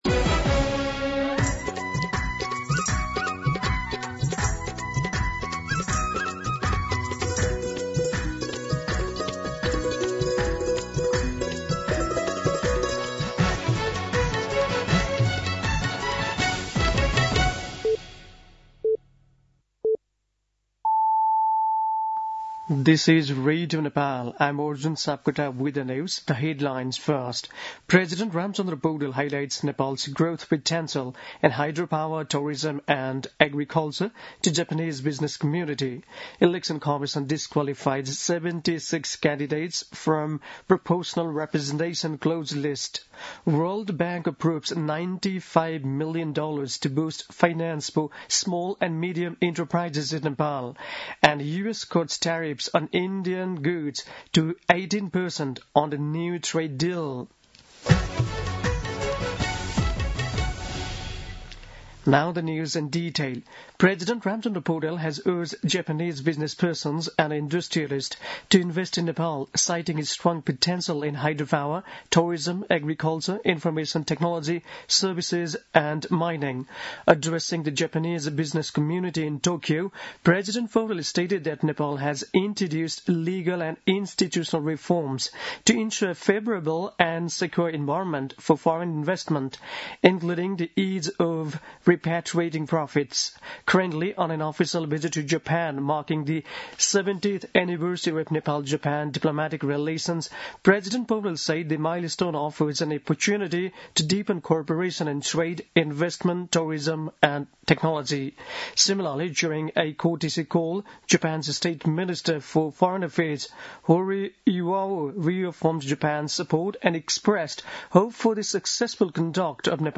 दिउँसो २ बजेको अङ्ग्रेजी समाचार : २० माघ , २०८२
2pm-News-10-20.mp3